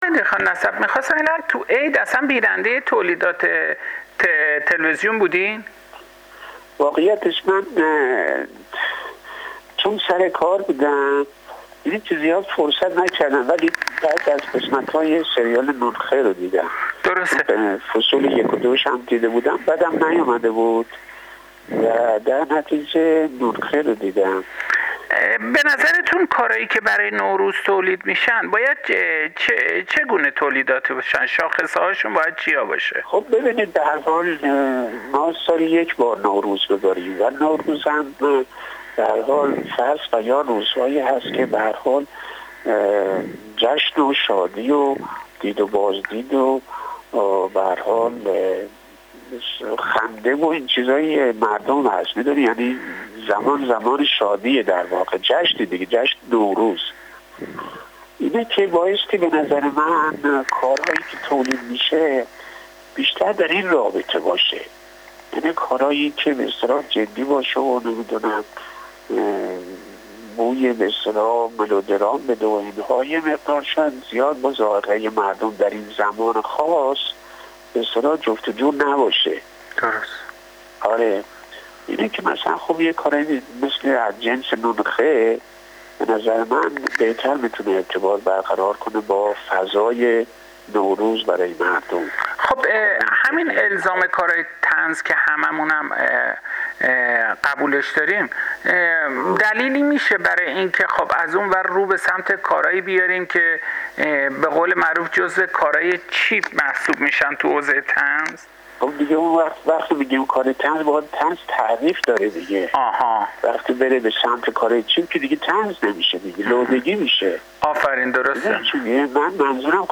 حبیب دهقان‌نسب، بازیگر سینما و تلویزیون در گفت‌وگو با خبرگزاری حوزه، به الزامات تولیدات نمایشی سیما در ایام نوروز اشاره کرد و گفت: نوروز برای همه ایرانیان با هر دینی و نژادی که هستند شادترین لحظات سال است.